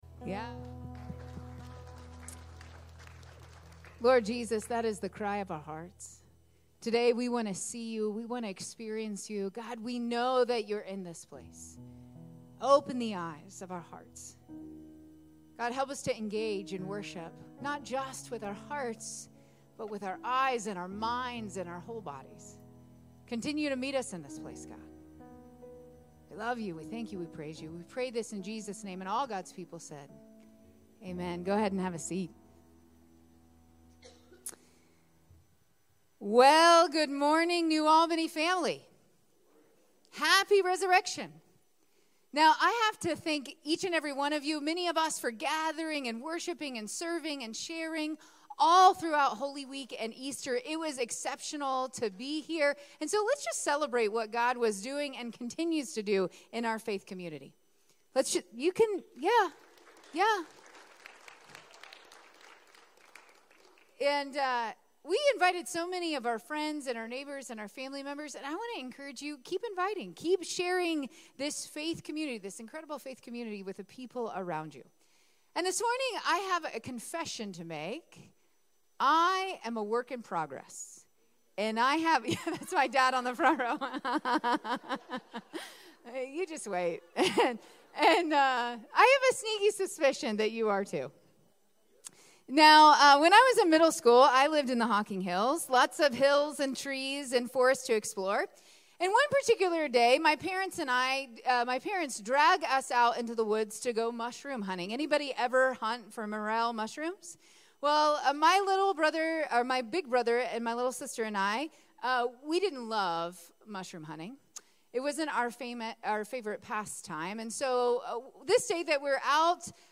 April 7, 2024 Sermon